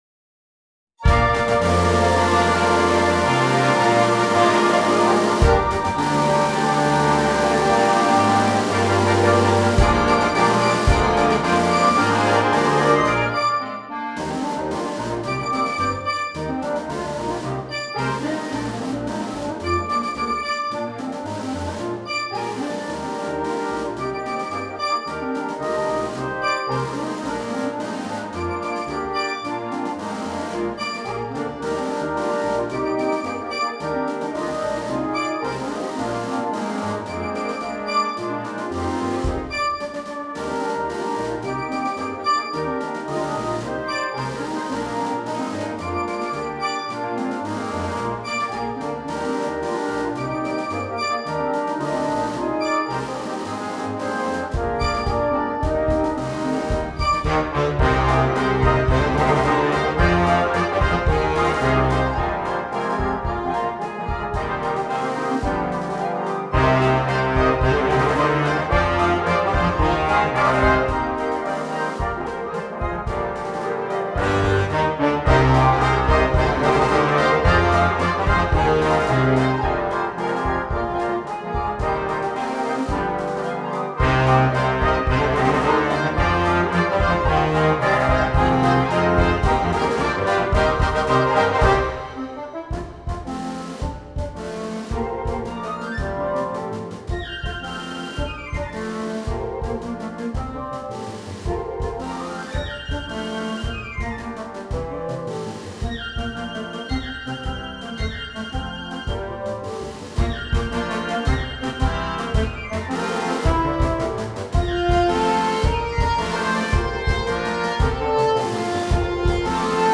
für großes Blasorchester…